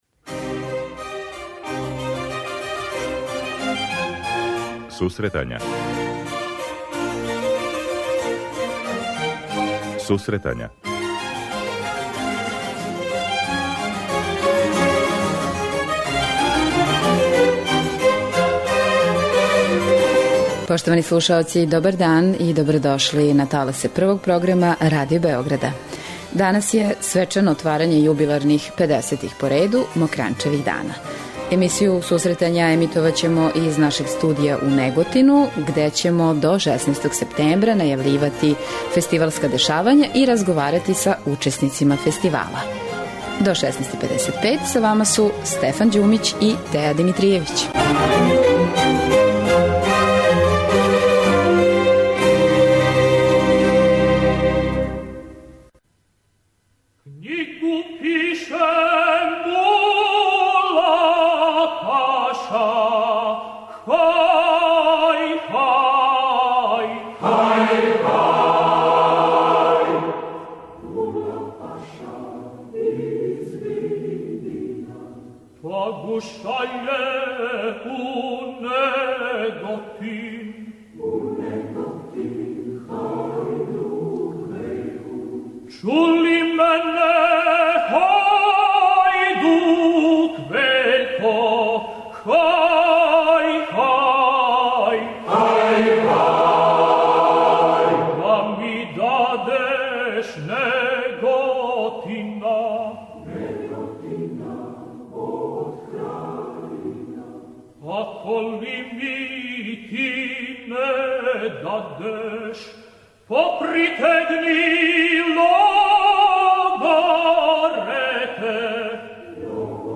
Данас у Неготину почињу 50. 'Мокрањчеви дани'. Екипа Радио Београда 1 од данас па до краја фестивала, 16. септембра, емисију 'Сусретања' реализује из тог града.